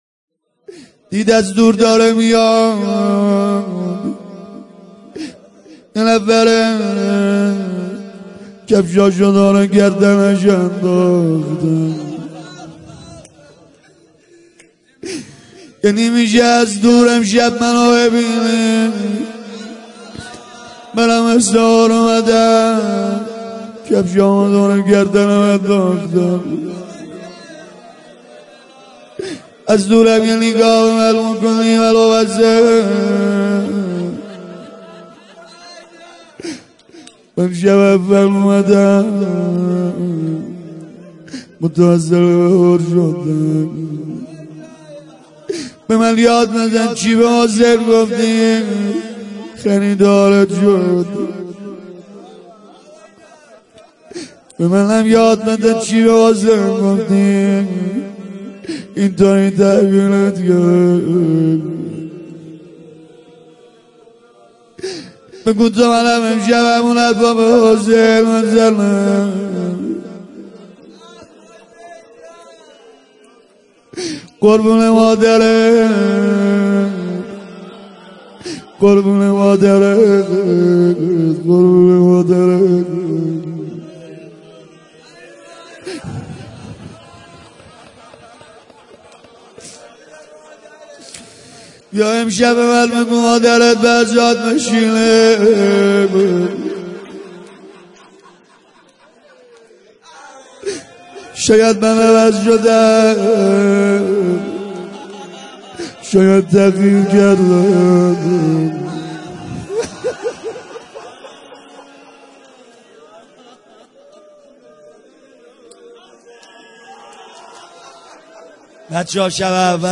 مراسم شب اول ماه رمضان با مداحی کربلایی سید رضا نریمانی در هیات فداییان حسین اصفهان برگزار شد